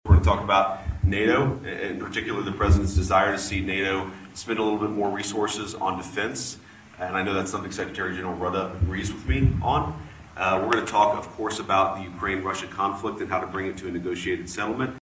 VICE PRESIDENT J-D VANCE IS AT THE MUNICH SECURITY CONFERENCE IN GERMANY TO MEET WITH NATO LEADERS .THE VICE PRESIDENT LAYS OUT WHAT HE AND THE NATO SECRETARY GENERAL WILL TALK ABOUT…